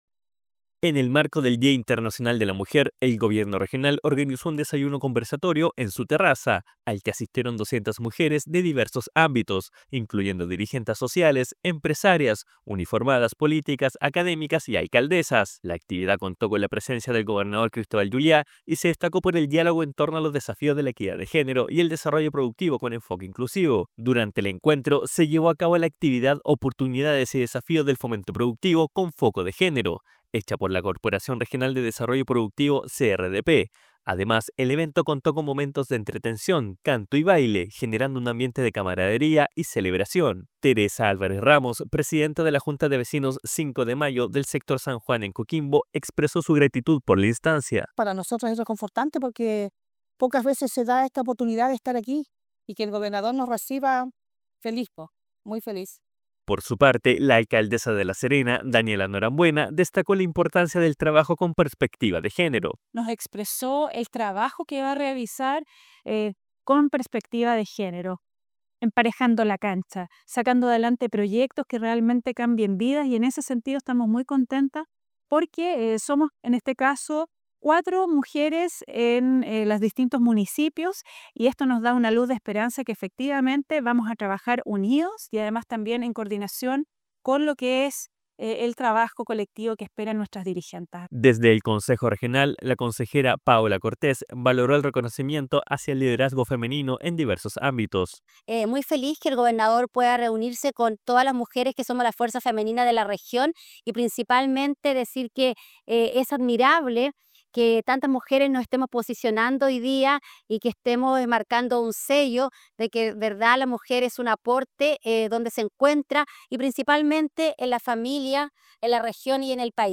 DESPACHO-RADIAL-ENCUENTRO-DE-MUJERES-8-GOBIERNO-REGIONAL.mp3